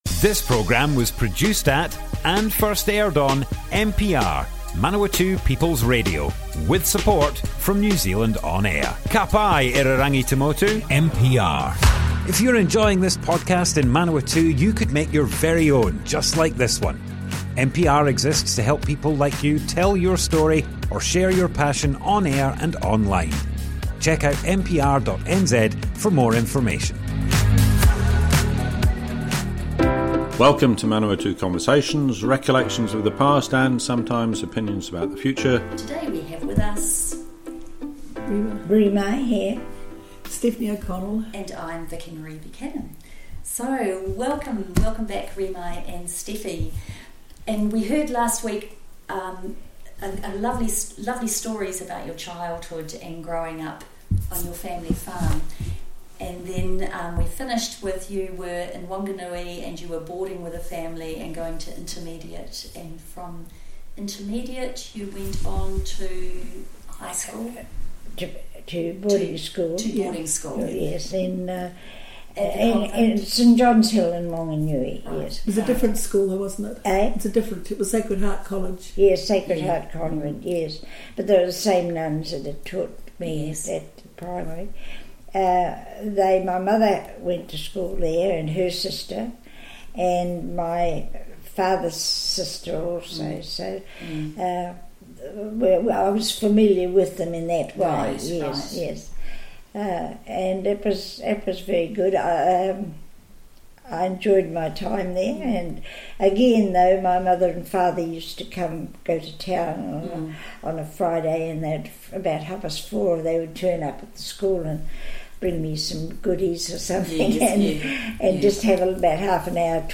Manawatu Conversations Object type Audio More Info → Description Broadcast on Manawatu People's Radio, 11th April 2023.
oral history